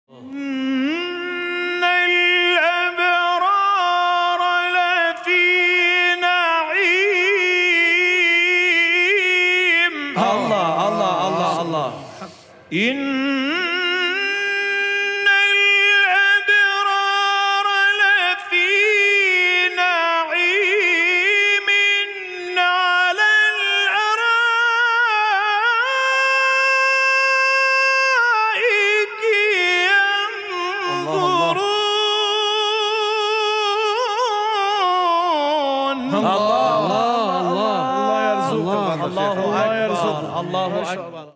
تلاوة جميلة للقارئ العراقي في Sound Effects Free Download